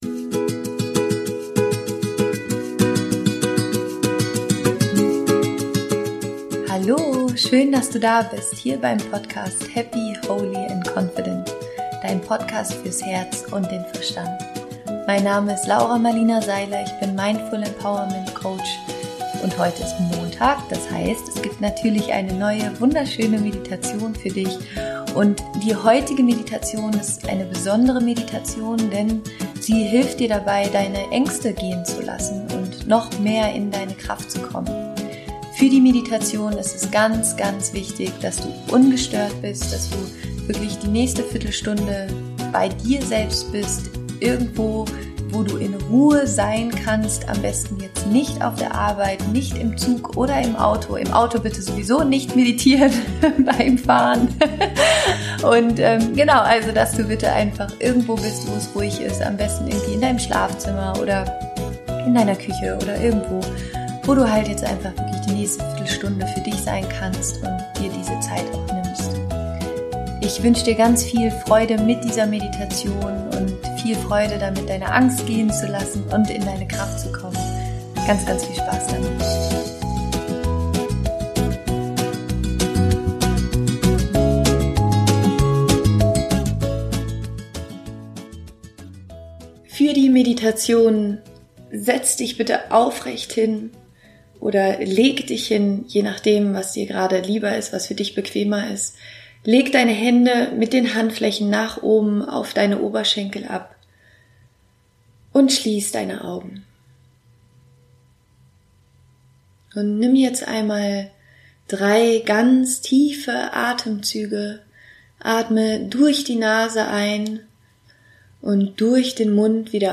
Meditation um deine Ängste loszulassen